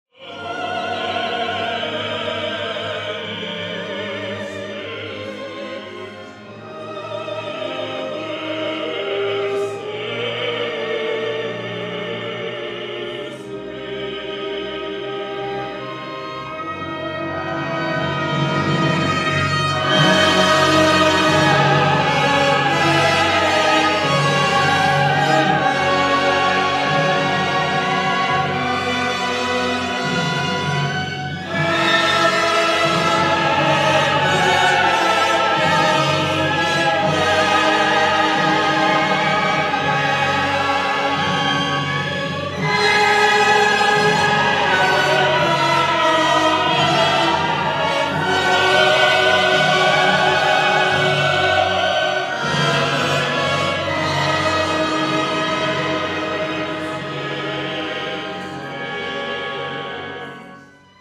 для солистов, хора и оркестра